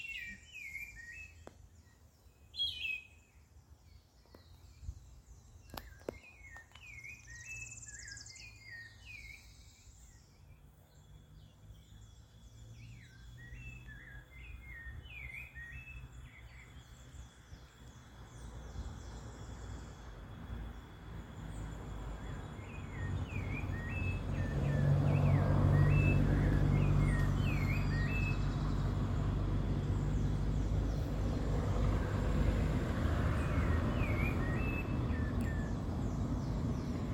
Rufous-bellied Thrush (Turdus rufiventris)
Province / Department: Tucumán
Location or protected area: San Miguel, capital
Condition: Wild
Certainty: Recorded vocal
zorzal-colorado-mp3.mp3